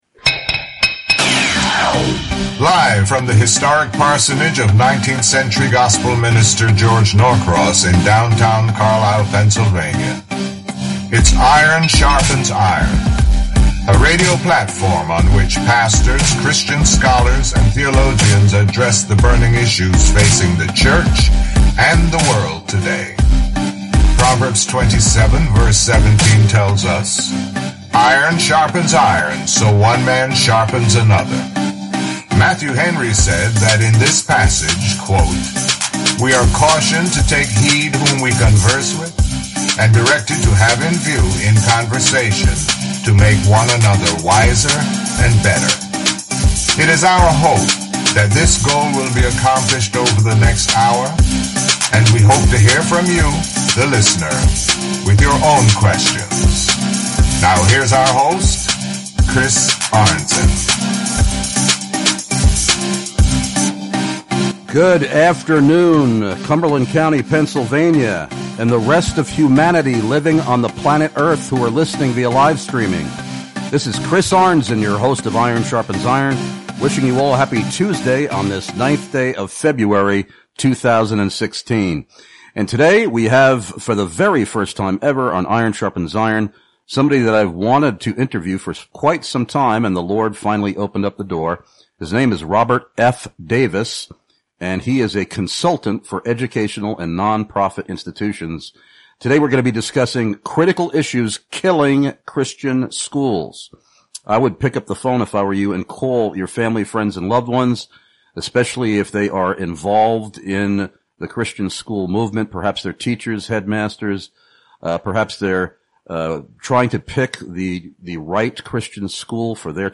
Perhaps the following will be useful for your website, the sources I quoted during our interview: